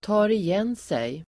Ladda ner uttalet
ta igen sig verb, recoverGrammatikkommentar: A &Uttal: [ta:rij'en:sej] Böjningar: tog igen sig, tagit igen sig, tag igen sig, taga igen sig, tar igen sigDefinition: vila